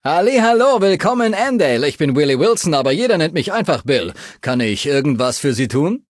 Datei:Maleadult01default dialogueandale greeting 0002e33d.ogg
Fallout 3: Audiodialoge